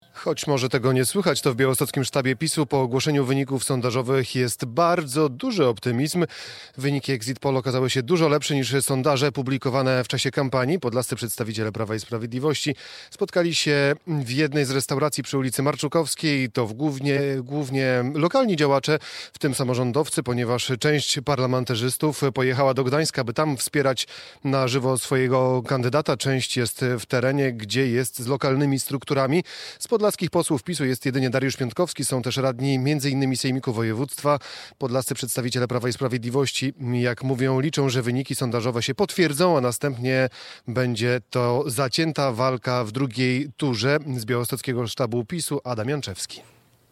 Pierwsze reakcje po ogłoszeniu sondażowych wyników wyborów prezydenckich w białostockim sztabie Karola Nawrockiego - relacja